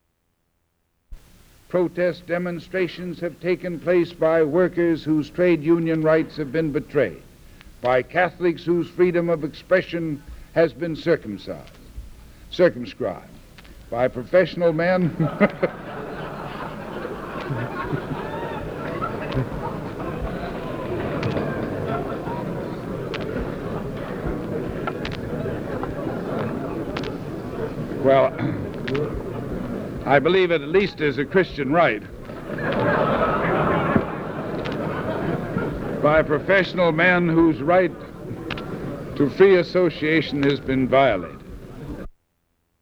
Adlai Stevenson speaks at the United Nations